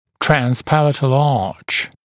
[ˌtræns’pælətl ɑːʧ][ˌтрэнс’пэлэтл а:ч]небный бюгель, небная дуга